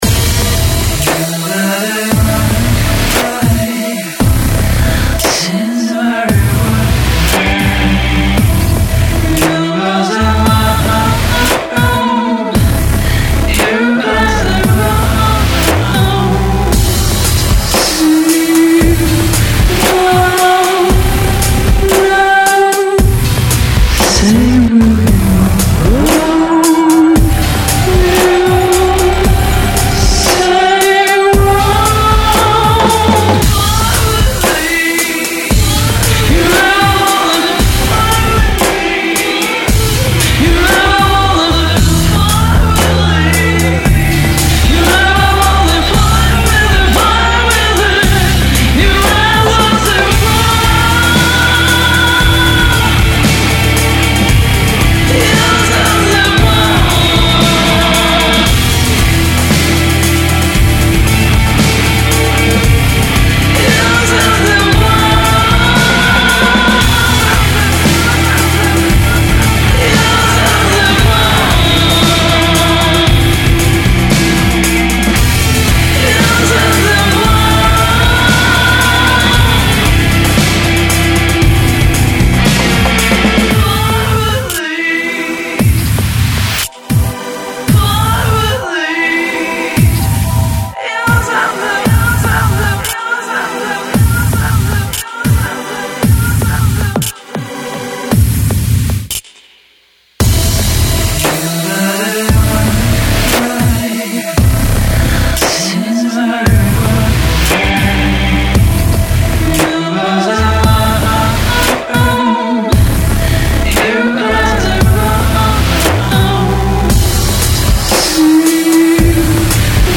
Stok 12 - FEAR (demo)